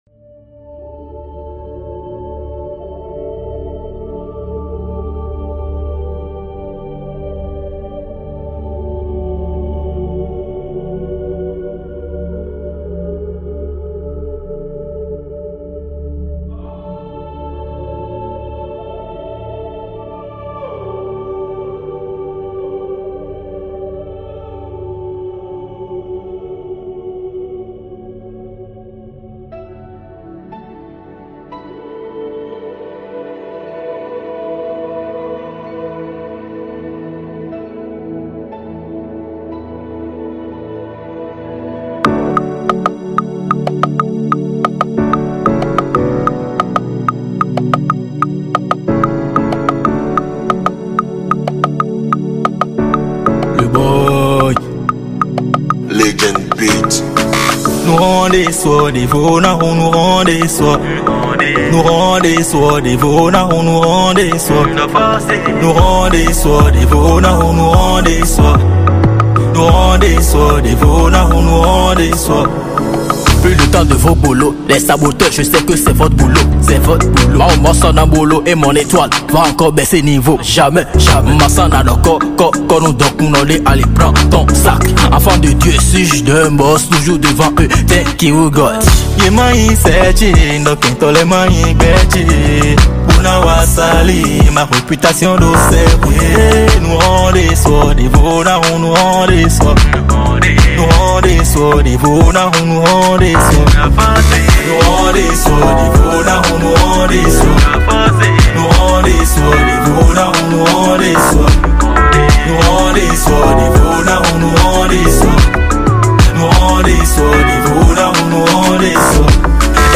Urban Mp3